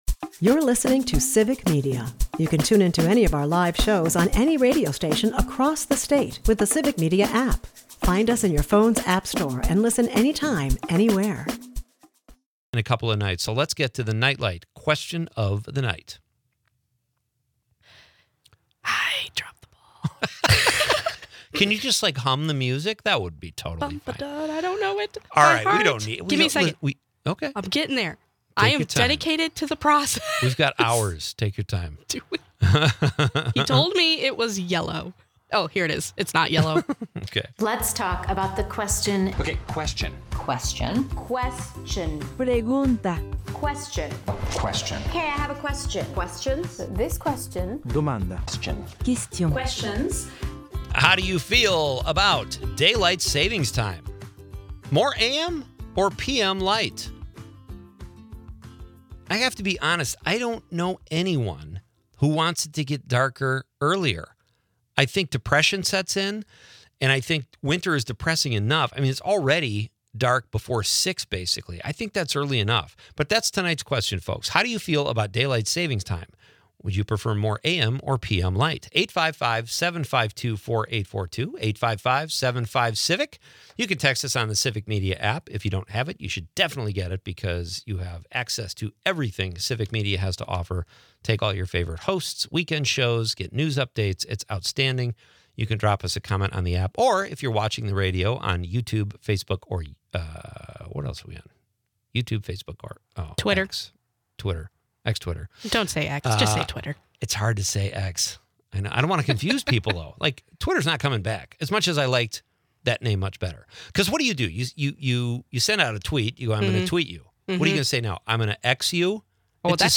The episode kicks off with a spirited debate over daylight savings time, with hosts and callers weighing in on whether they prefer more morning or evening light.